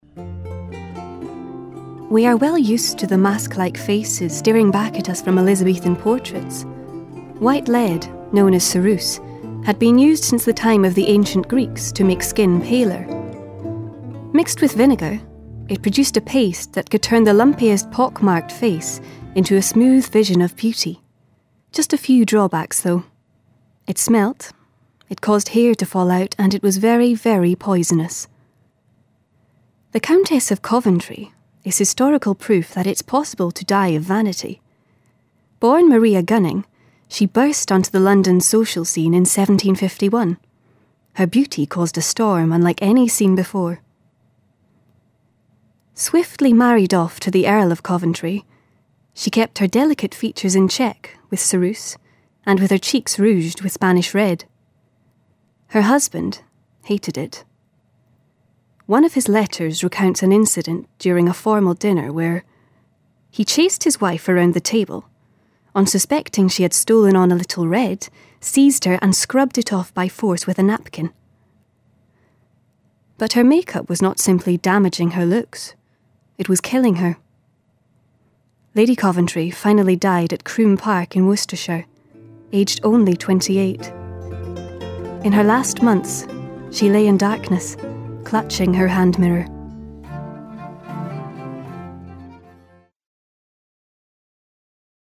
SCOTS. Smooth and calming to upbeat irreverence. Personable lass. A natural VO. Avid Muso.
Her accents range from Scottish East Coast, Edinburgh and Highlands to RP and Irish, and voice age ranging from teens to late 20s.